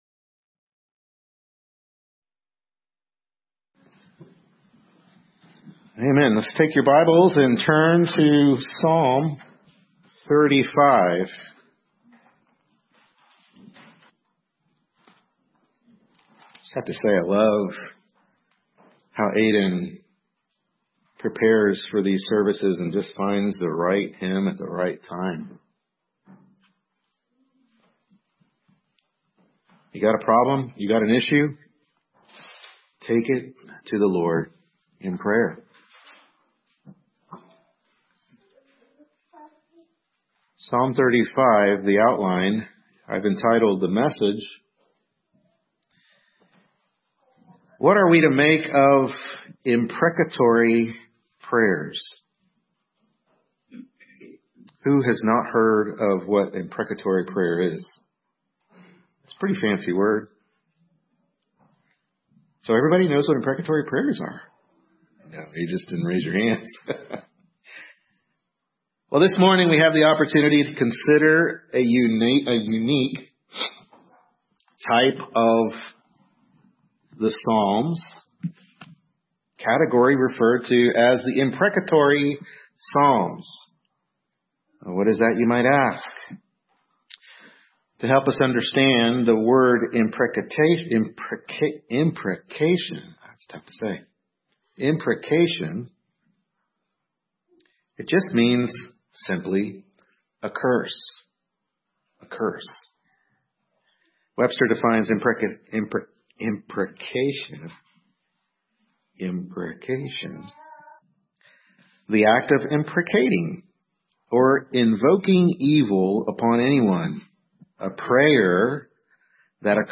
Psalm 35 Service Type: Morning Worship Service Psalm 35 What Are We to Make of Imprecatory Prayers?